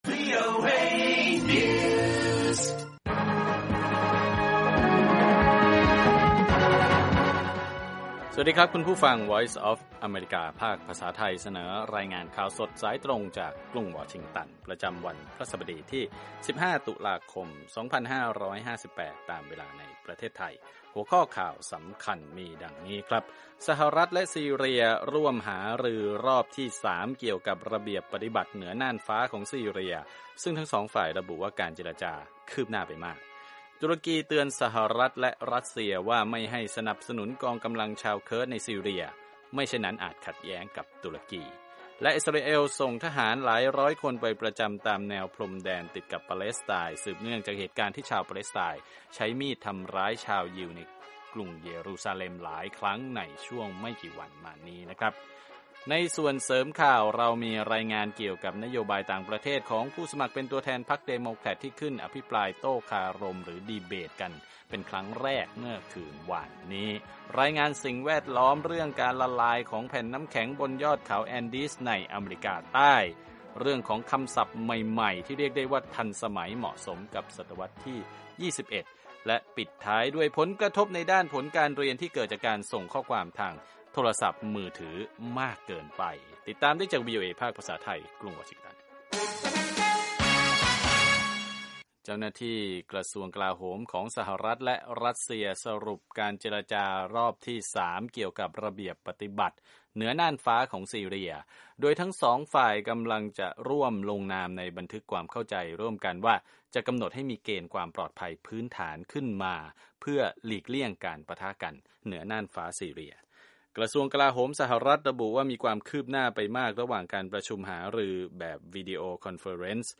ข่าวสดสายตรงจากวีโอเอ ภาคภาษาไทย 6:00 – 6:30 น. พฤหัสบดี 15 ต.ค. 2558